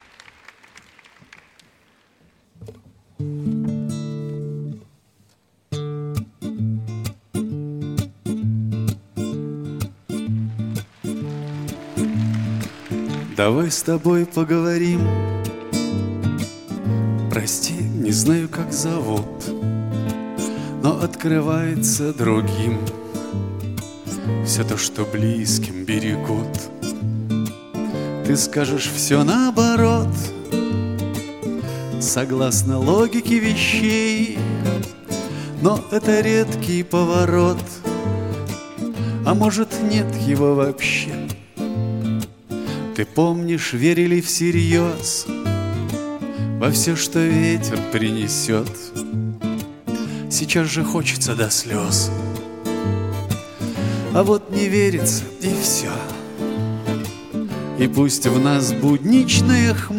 Pop
записанный во время концертов в Кремле.